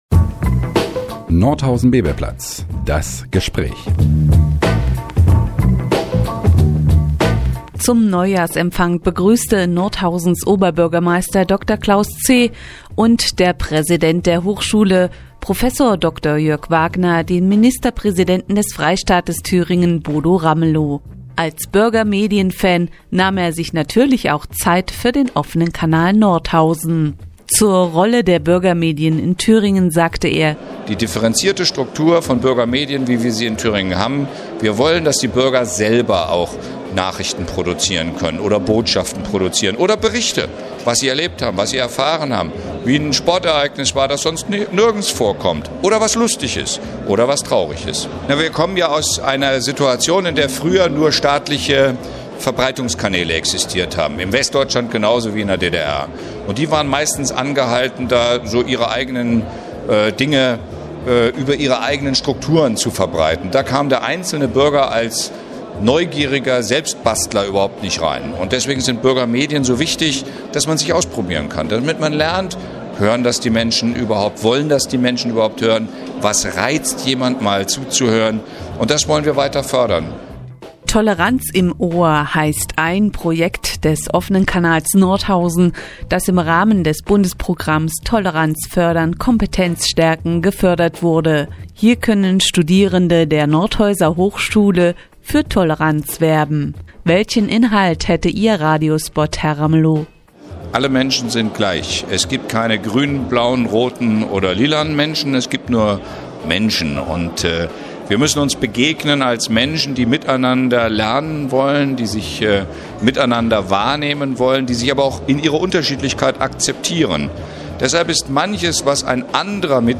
Das Interview mit Bodo Ramelow gibt es in Ihrer nnz als